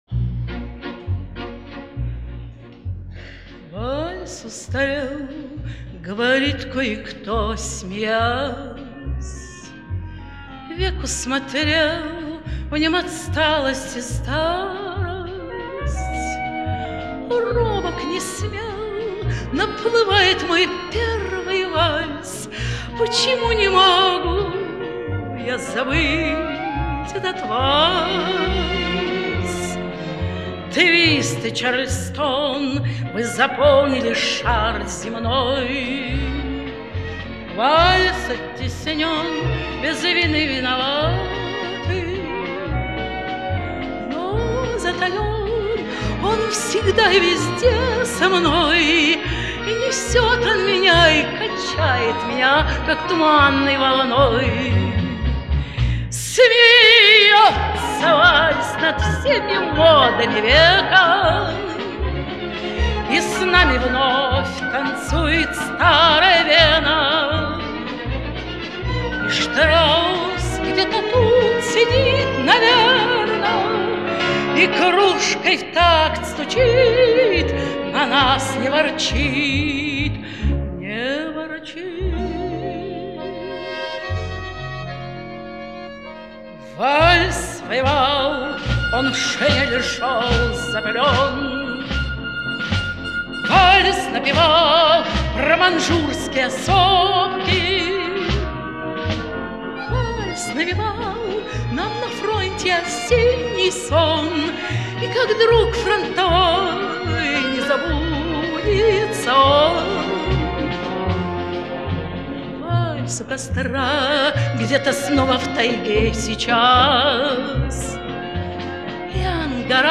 Её исполнению присуща глубокая задушевность.